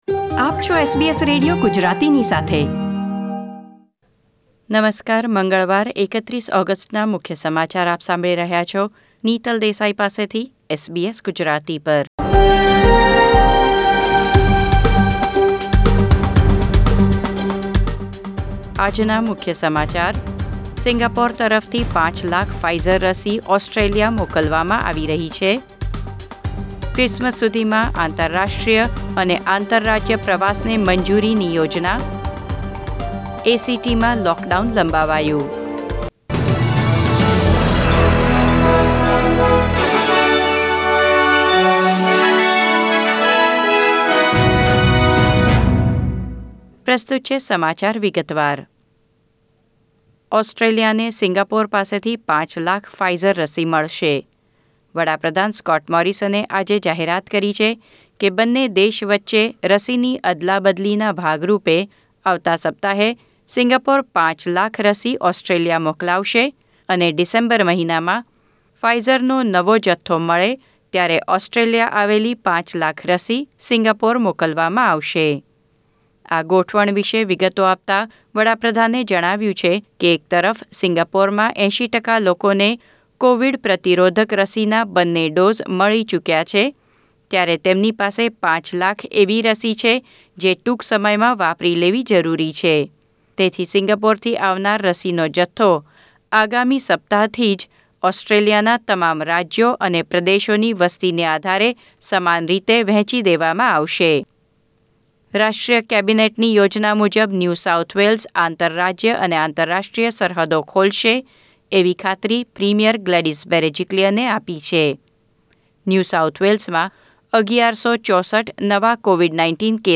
SBS Gujarati News Bulletin 31 August 2021